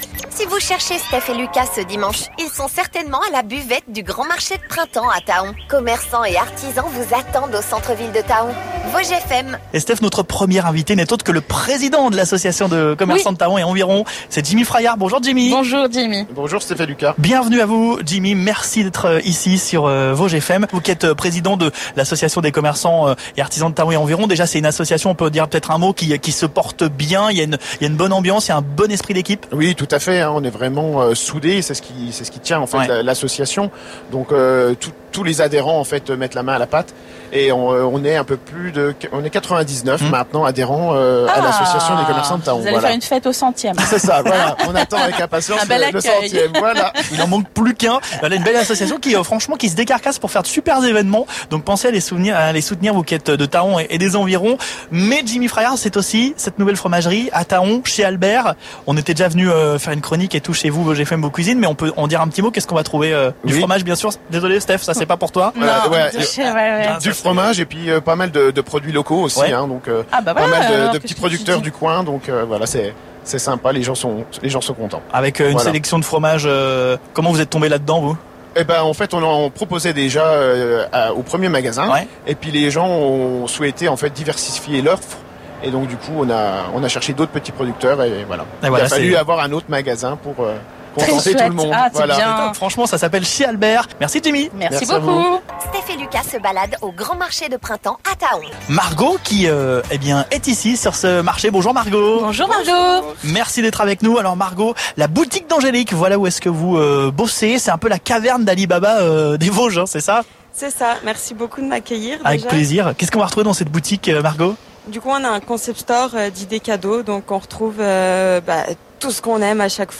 Au programme, l'interview de Cédric Haxaire, le maire de Thaon-les-Vosges.